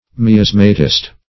Miasmatist \Mi*as"ma*tist\, n. One who has made a special study of miasma.